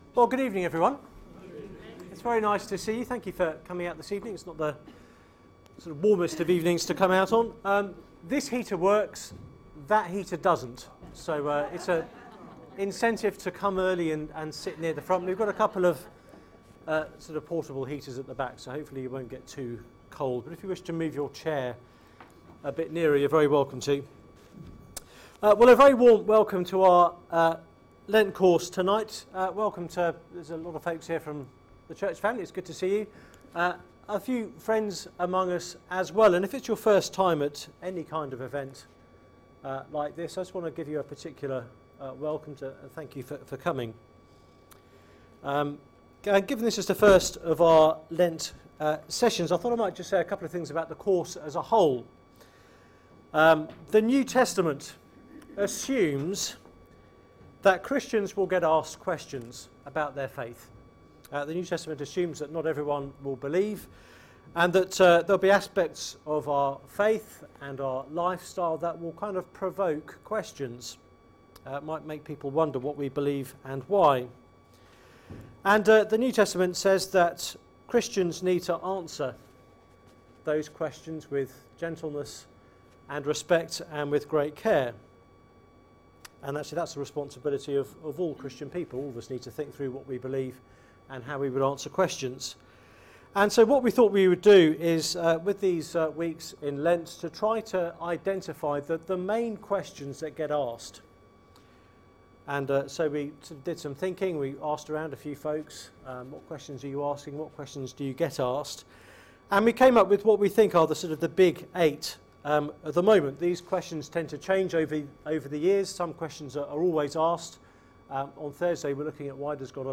Media for Seminar on Mon 25th Feb 2013 20:00 Speaker